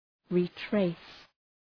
Προφορά
{rı’treıs}